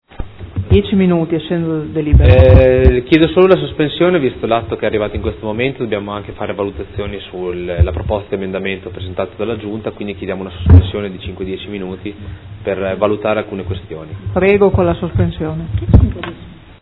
Seduta del 26/11/2015 Delibera. Riorganizzazione della rete scolastica e costituzione degli Istituti Comprensivi. Chiede sospensione lavori di 10 minuti.